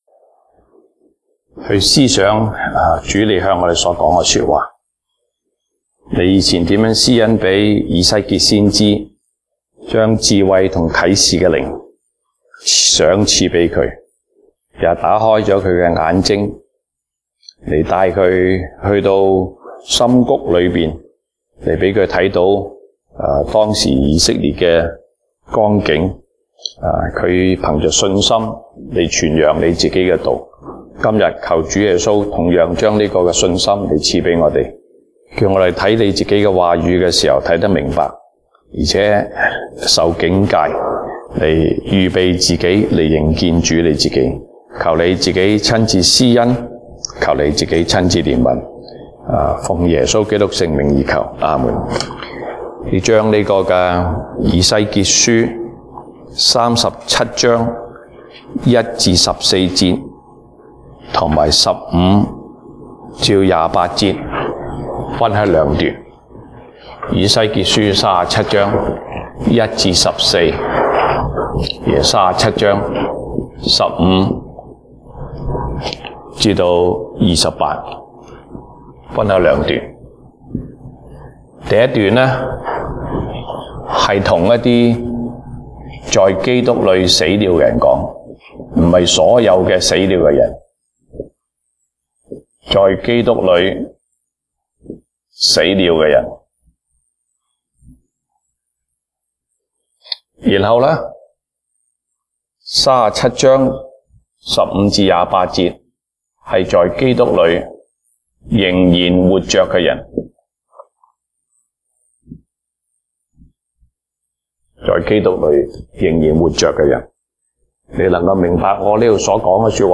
東北堂證道 (粵語) North Side: 復活與復國